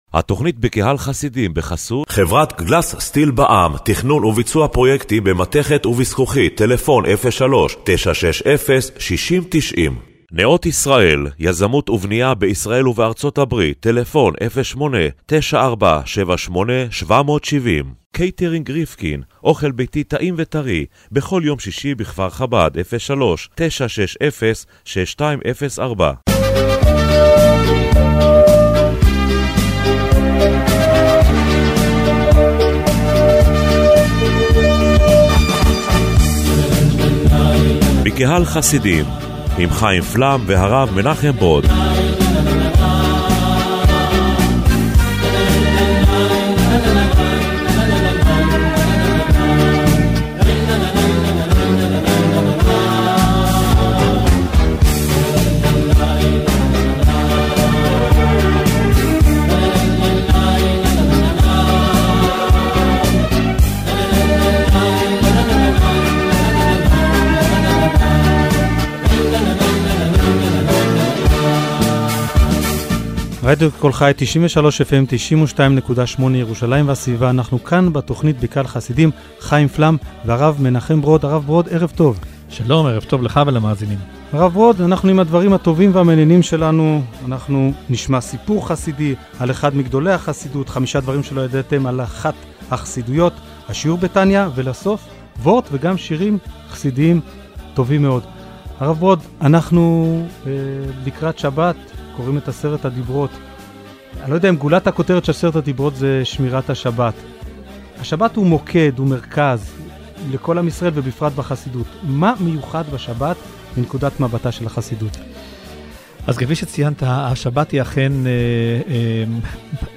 בתכנית הרדיו השבועית בקהל חסידים השבוע נדונה ההתייחסות של החסידות לשבת, ואיך החסידות רואה את עניינו של יוןם השבת ● שי...